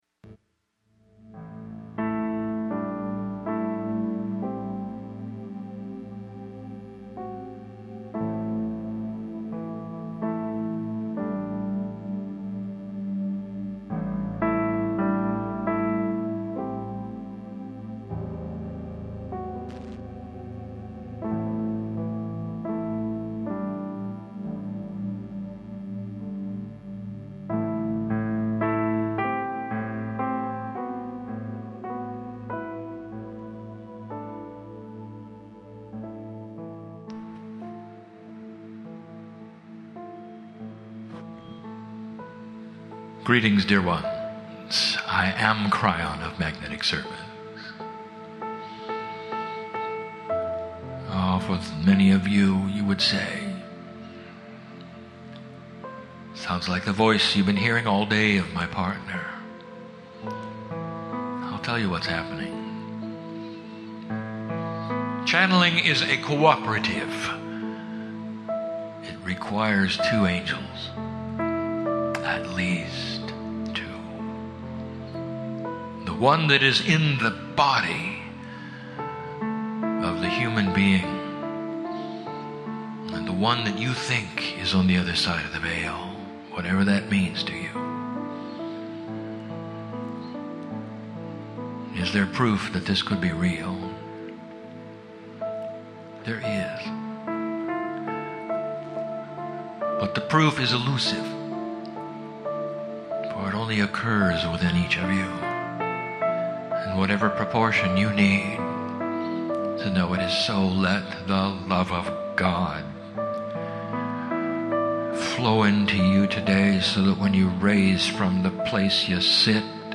Seminar Day #1 36 minute channelling